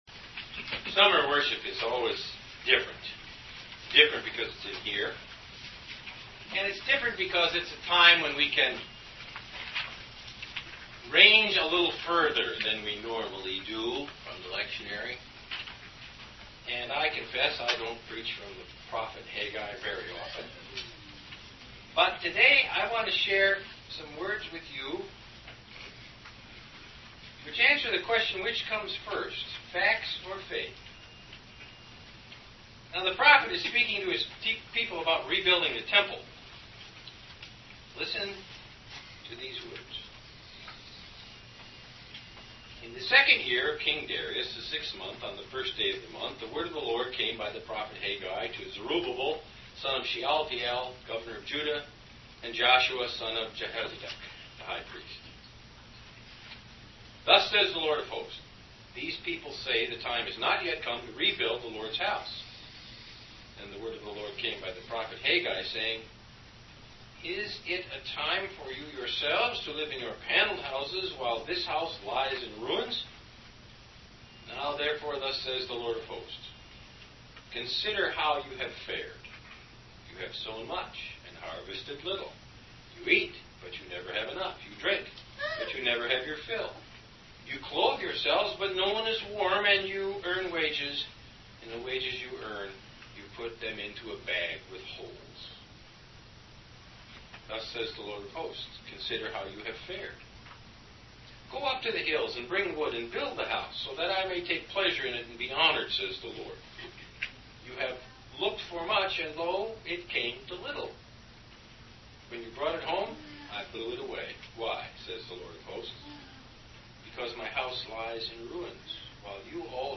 Sermon for August 1, 2010